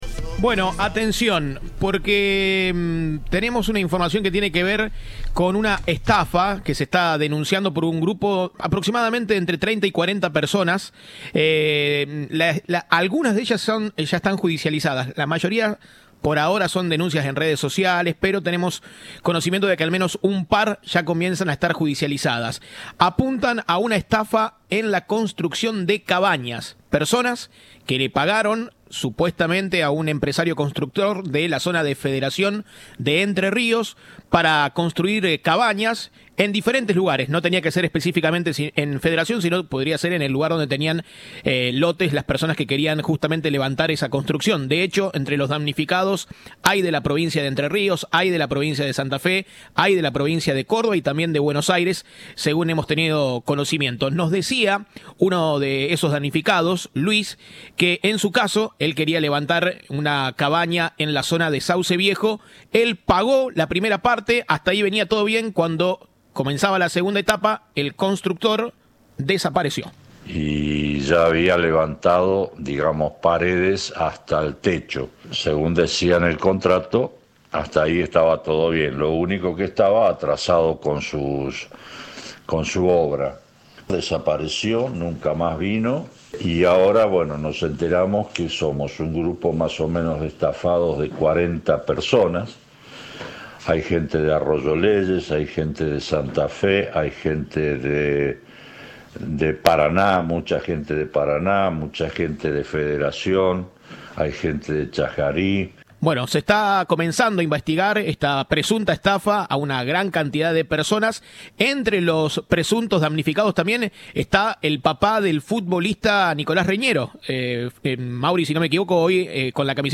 Uno de los damnificados dio su testimonio a Cadena 3.